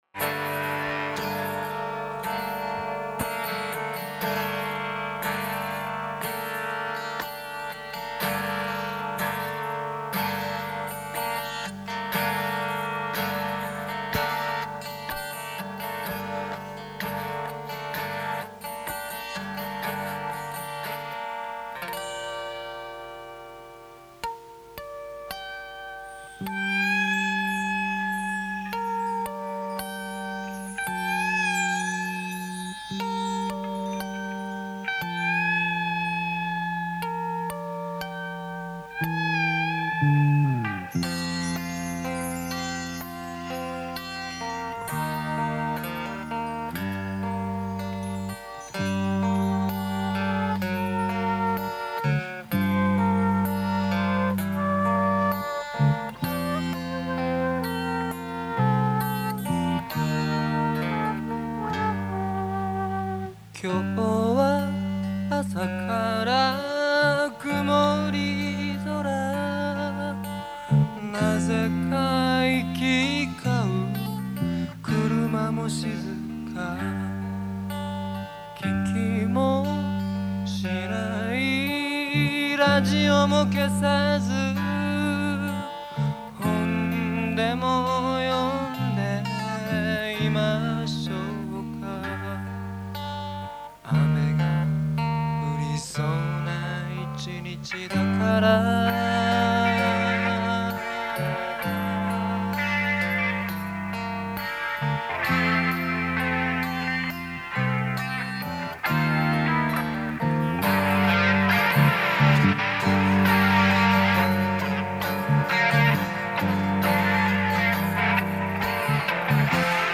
場所：神奈川公会堂
イベント名：横浜ふぉーく村コンサート